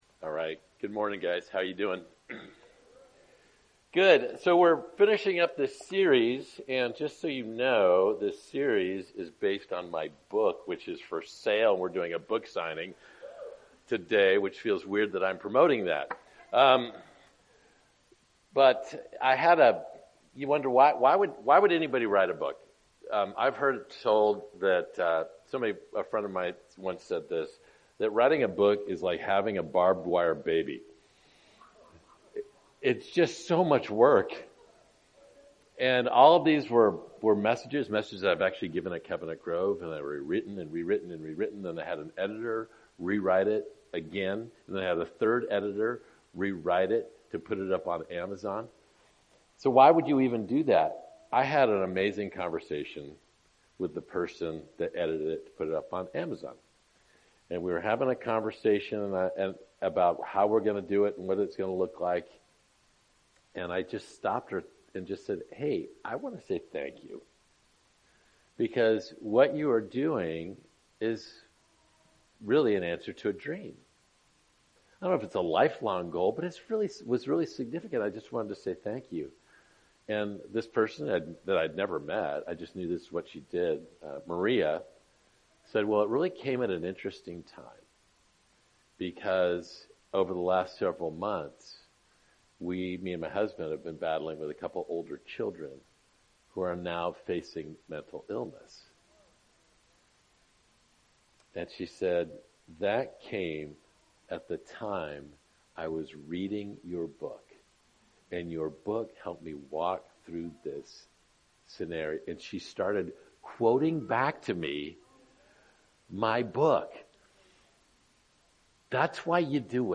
Sermons | Covenant Grove Church